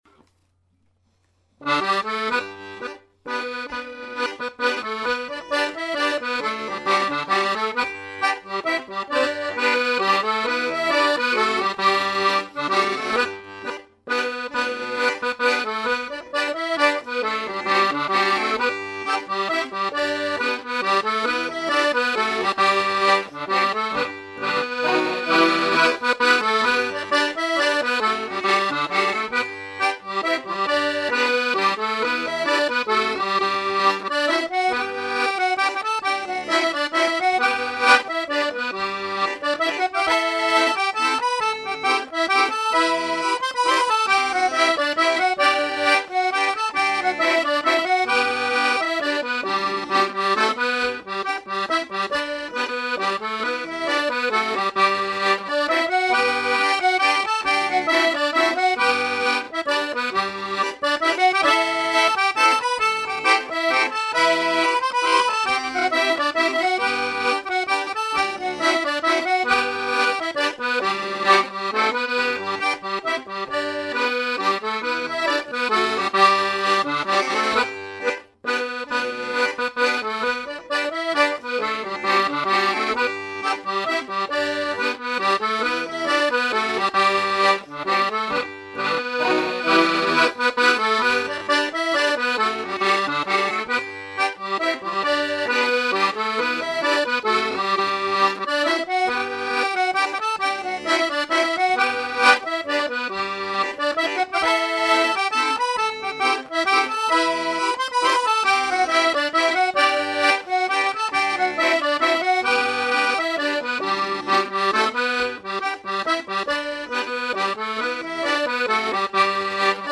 Clog Music 15% slower
Durham Waltz for three 160bpm